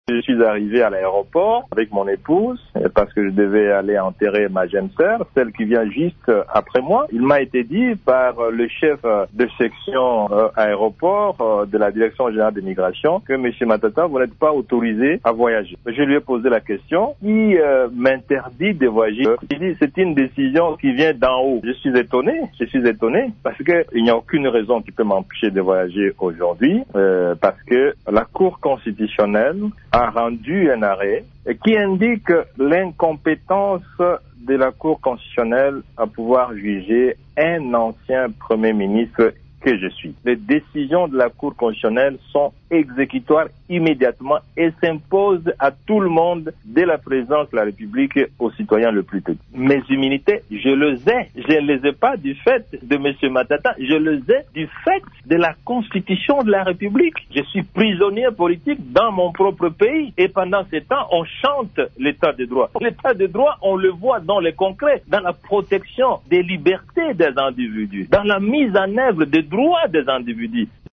Intervenant sur Radio Okapi ce dimanche, Matata Ponyo condamne cette décision qui, selon lui, bafoue les règles de l’Etat de droit :